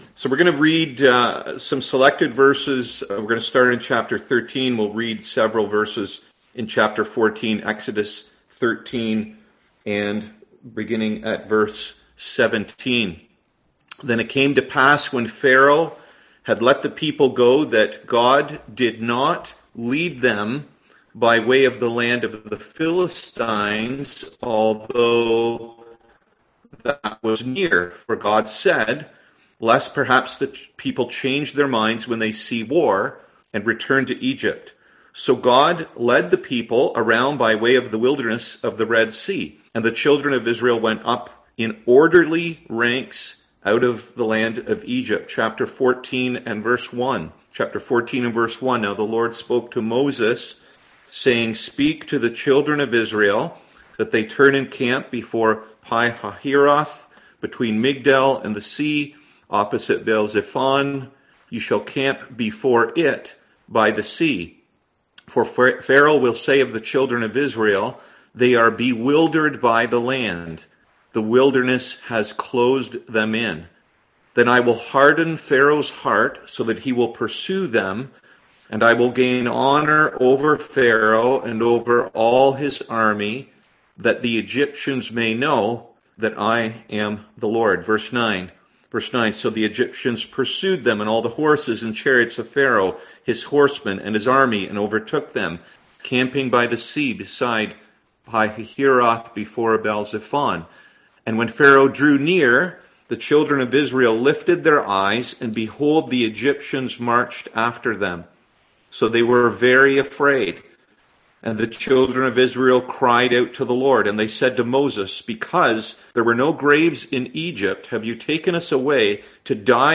Service Type: Sunday AM Topics: Doubt , Salvation , Trials